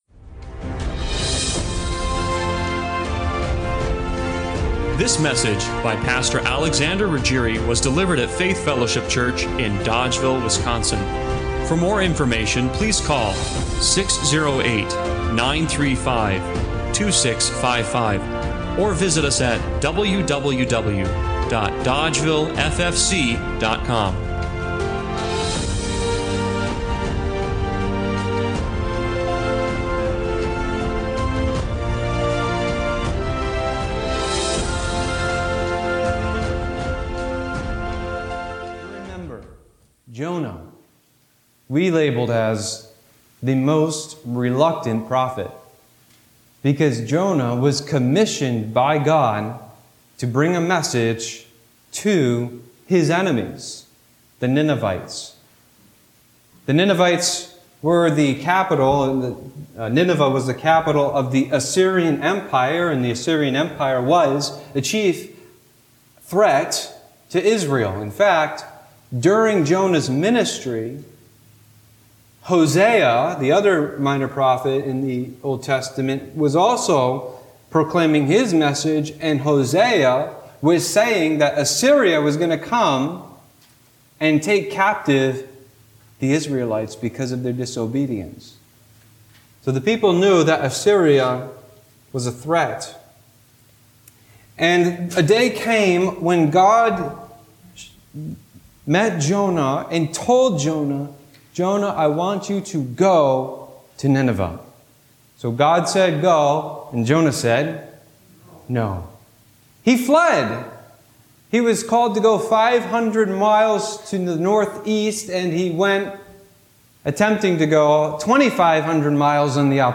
Jonah 4:1-11 Service Type: Sunday Morning Worship Why would a prophet run from God—Out of fear or frustration?